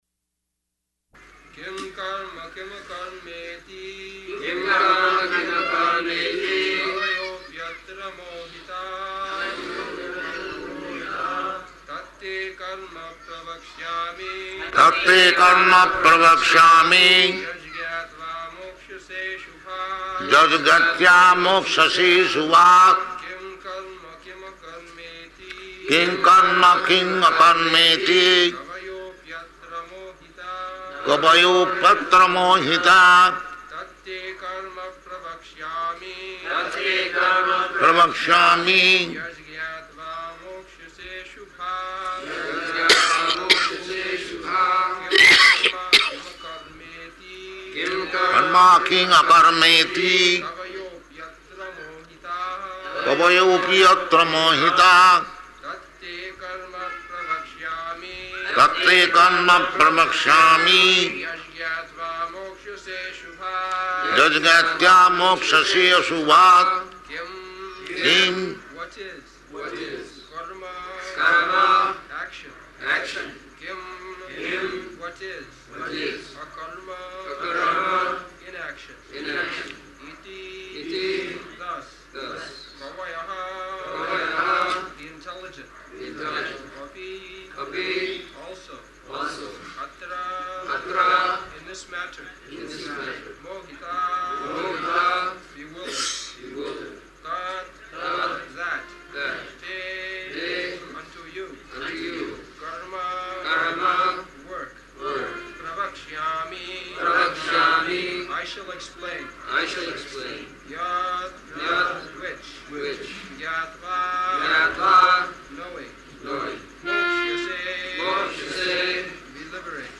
April 5th 1974 Location: Bombay Audio file
[Prabhupāda and devotees repeat, etc]